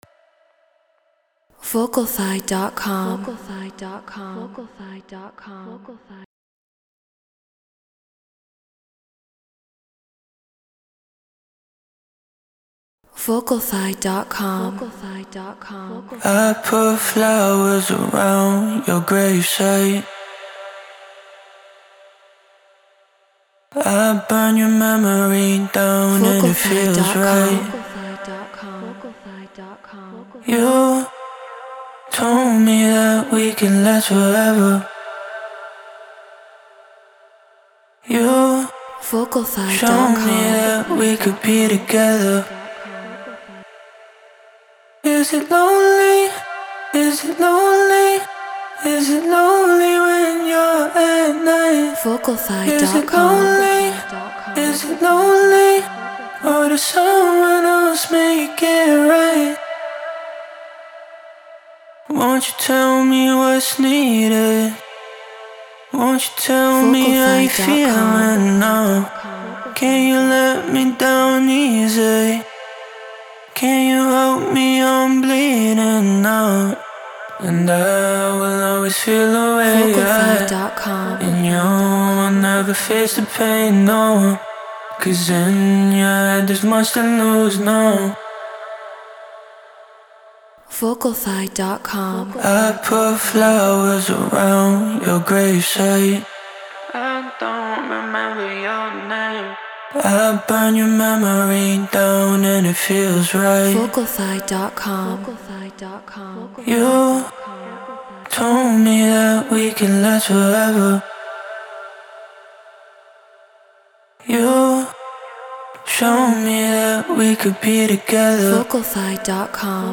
Deep House 126 BPM Dmin
Treated Room